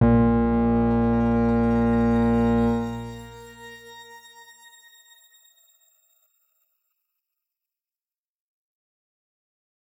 X_Grain-A#1-mf.wav